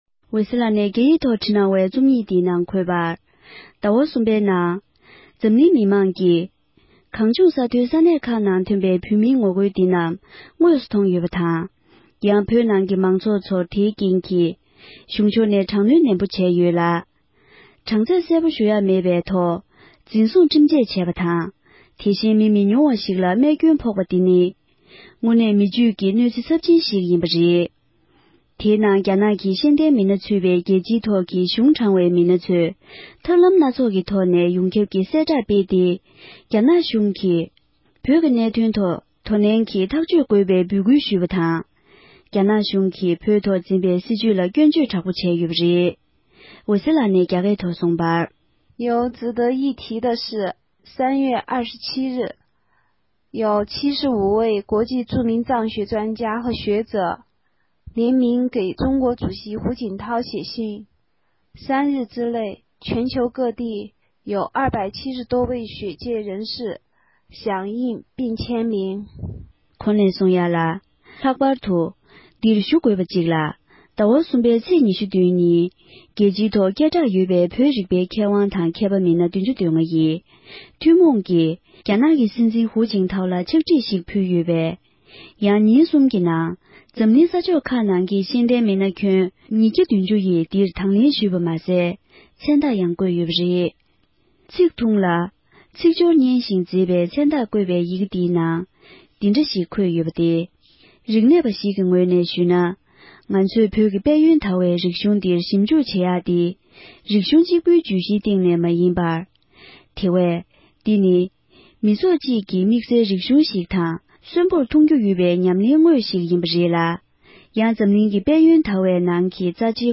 བོད་སྐད་ཐོག་ཕབ་བསྒྱུར་གྱིས་སྙན་སྒྲོན་ཞུས་པར